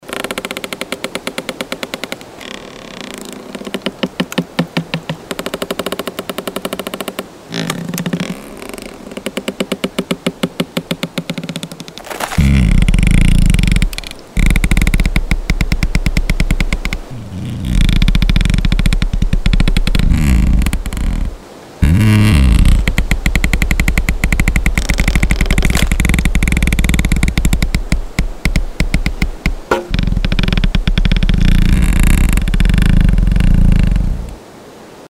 Звуки растущего дерева
На этой странице представлены редкие звуки растущего дерева — от едва уловимого шепота молодых побегов до глубоких вибраций старого ствола.